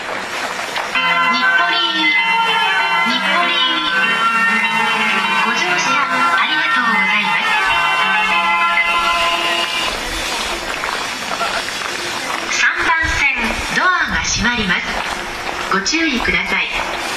ただ、京成線・新幹線・宇都宮線・高崎線の走行音で発車メロディの収録は困難です。